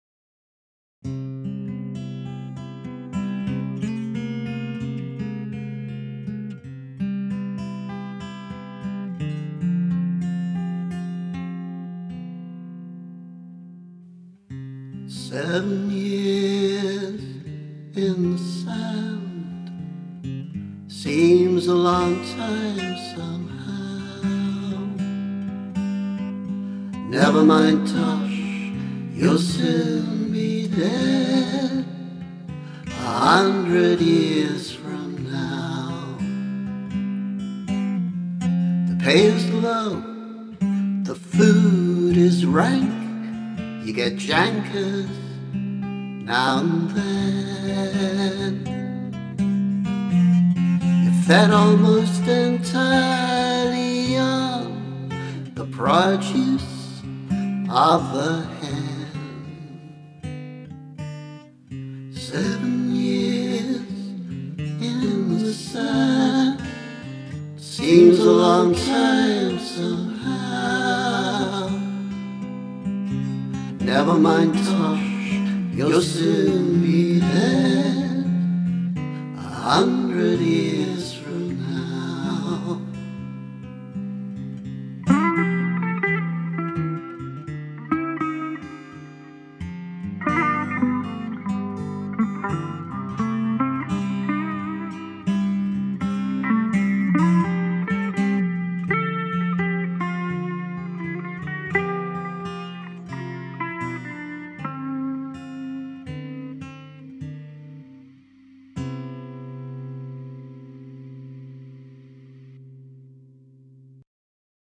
Seven Years in the Sand [demo]